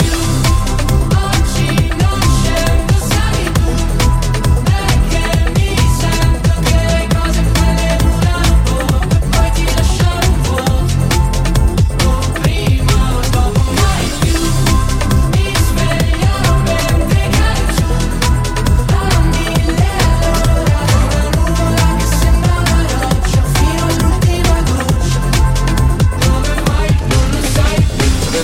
Genere: ,pop,trap,dance,rap,remix,italiana,hit